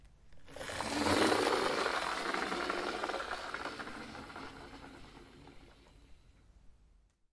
玩具车 " 玩具车推出
描述：在木地板上滚动的便宜，中型，塑料玩具汽车。用Zoom H1记录。